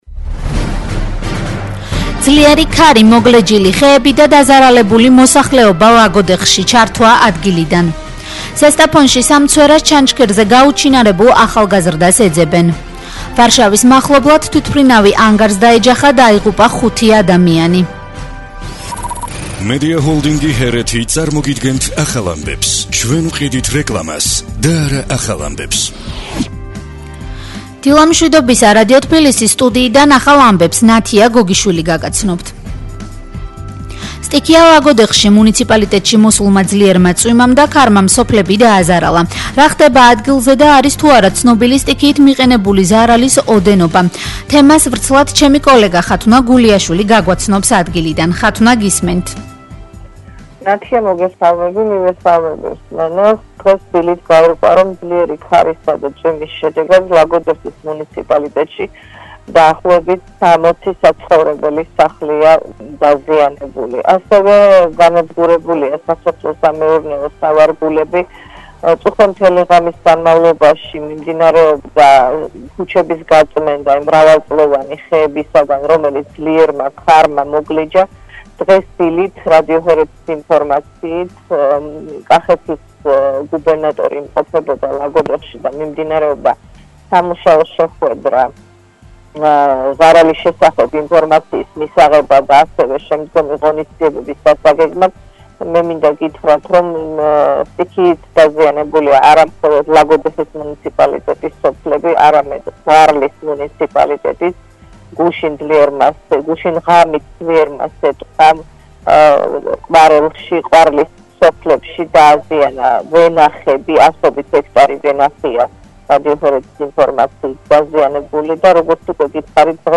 ძლიერი ქარი, მოგლეჯილი ხეები და დაზარალებული მოსახლეობა ლაგოდეხში - ჩართვა ადგილიდან ზესტაფონში, სამ
ახალი ამბები 10:00 საათზე